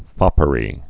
(fŏpə-rē)